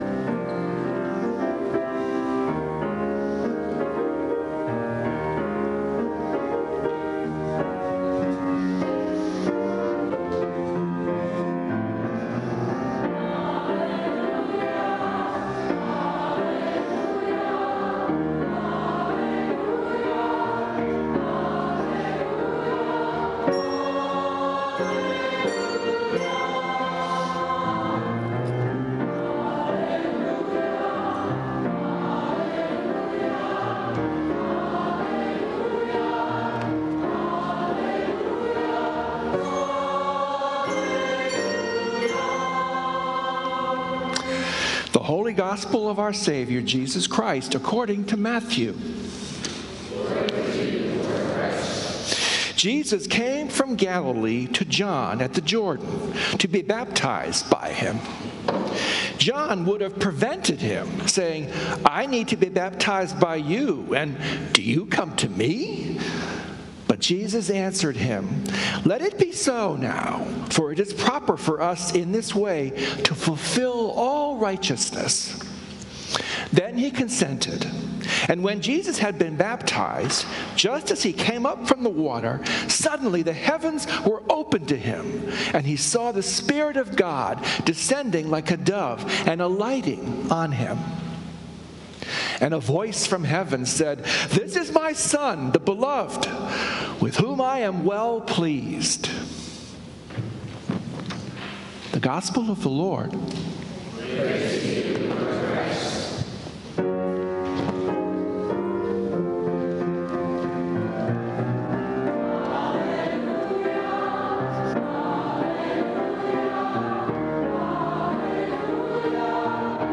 Sunday Sermon
Sermons from St. Columba's in Washington, D.C.